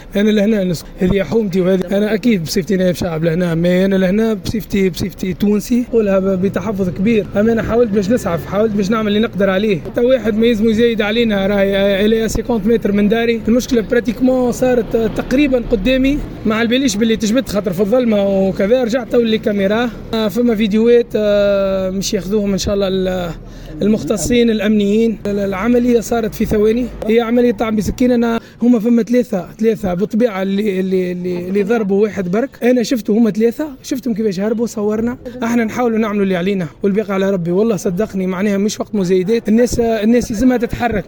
النائب بمجلس نواب الشعب عن ولاية صفاقس طارق المهدي يتحدث (تسجيل)